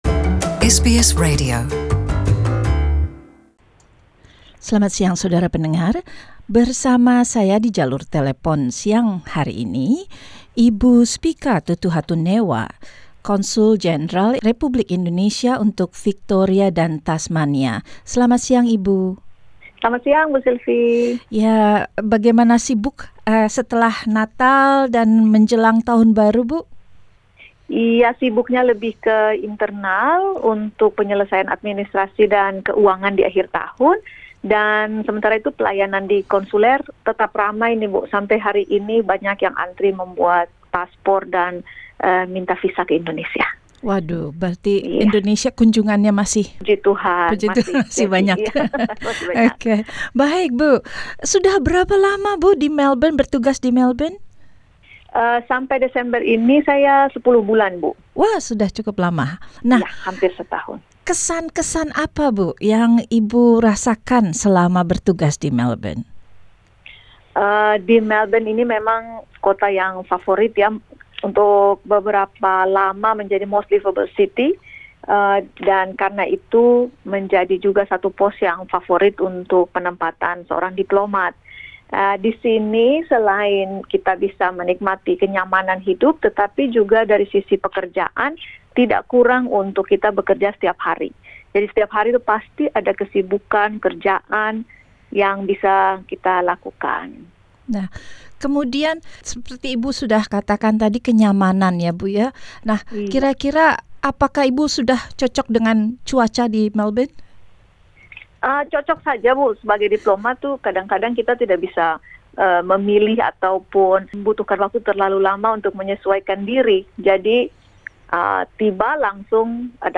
Secara lengkap beliau menyampaikannya dalam wawancara berikut ini.